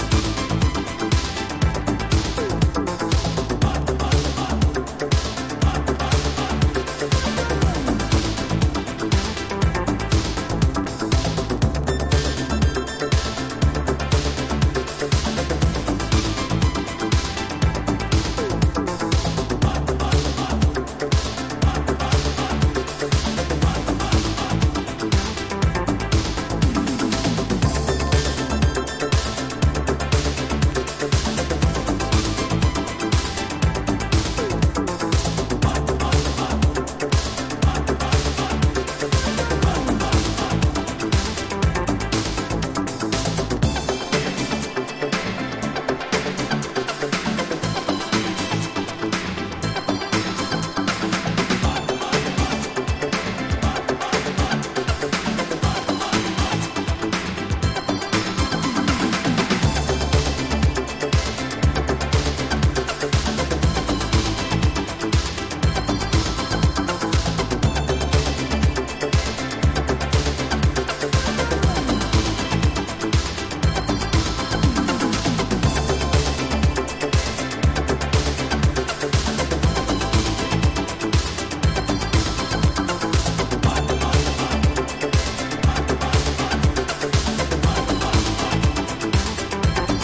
# DEEP HOUSE / EARLY HOUSE# NU-DISCO / RE-EDIT